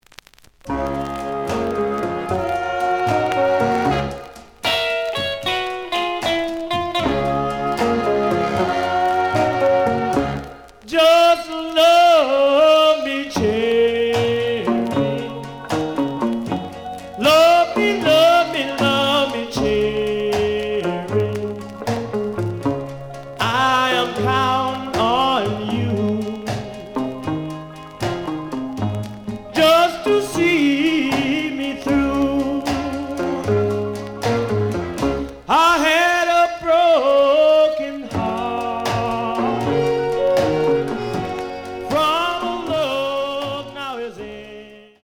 試聴は実際のレコードから録音しています。
The audio sample is recorded from the actual item.
●Genre: Rhythm And Blues / Rock 'n' Roll